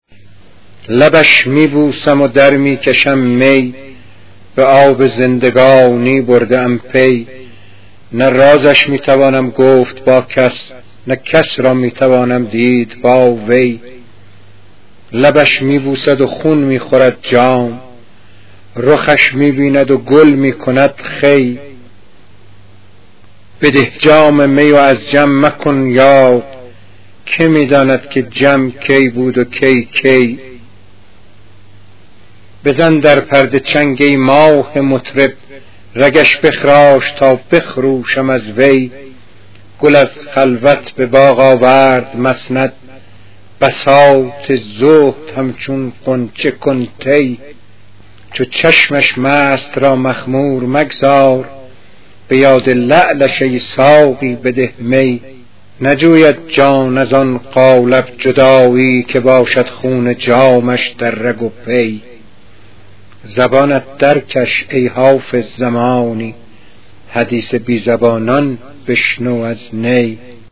پخش صوتی غزل